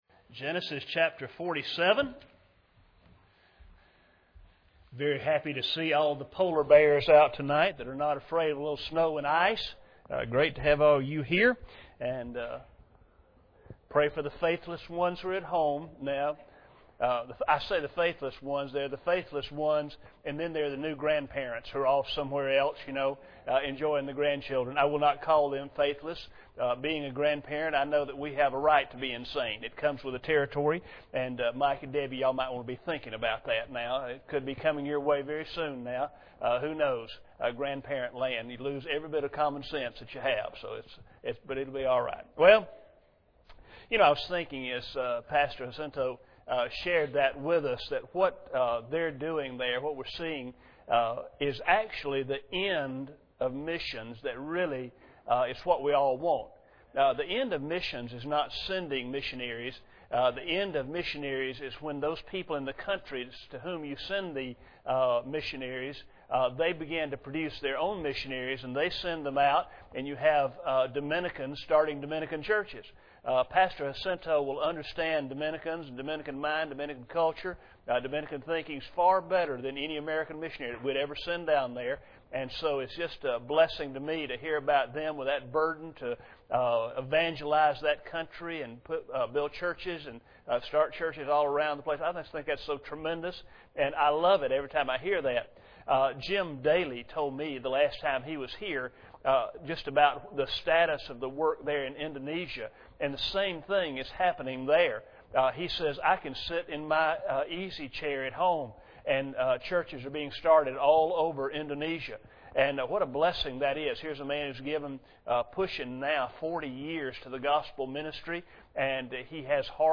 Life of Joseph Passage: Genesis 47:27 Service Type: Sunday Evening Bible Text